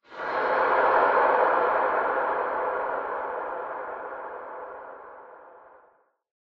Sound / Minecraft / ambient / cave / cave4.ogg
cave4.ogg